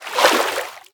latest / assets / minecraft / sounds / liquid / swim12.ogg
swim12.ogg